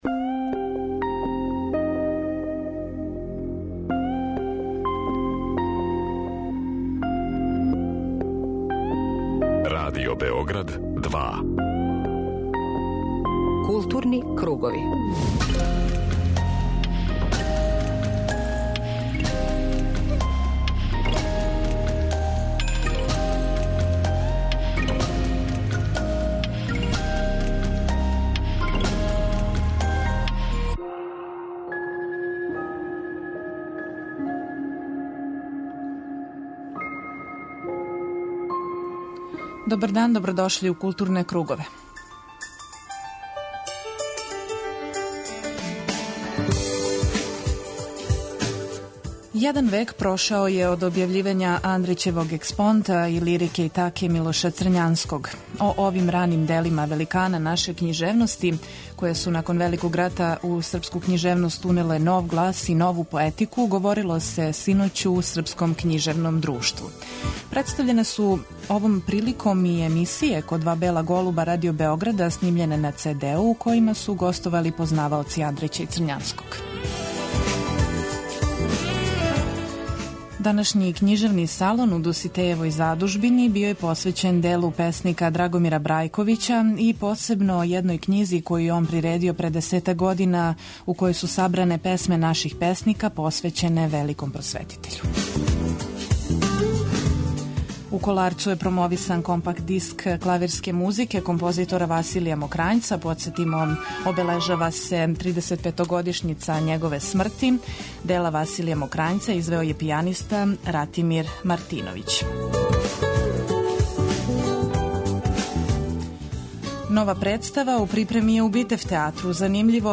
Дневни магазин културе Радио Београда 2